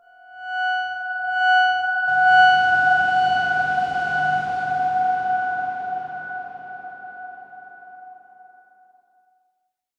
X_Darkswarm-F#5-pp.wav